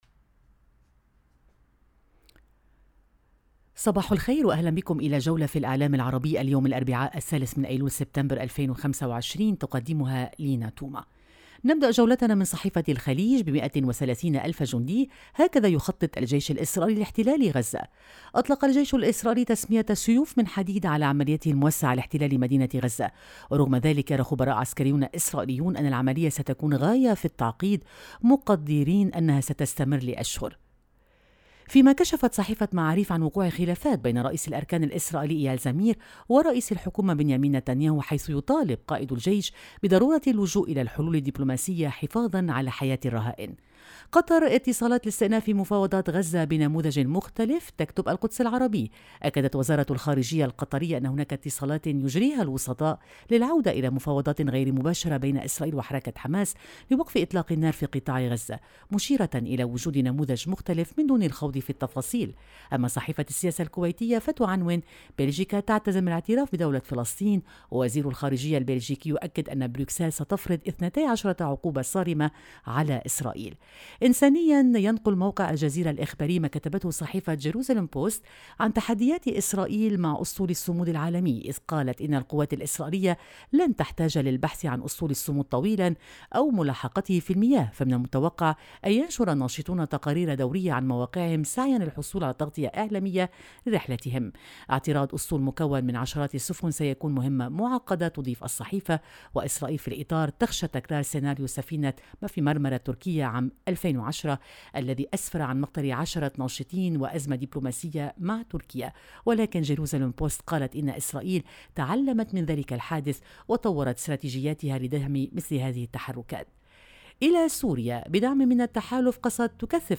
صدى المشرق – نافذتك اليومية على إعلام الشرق، كل صباح على إذاعة الشرق بالتعاون مع جريدة النهار اللبنانية، نستعرض أبرز ما جاء في صحف ومواقع الشرق الأوسط والخليج من تحليلات ومواقف ترصد نبض المنطقة وتفكك المشهد الإعلامي اليومي.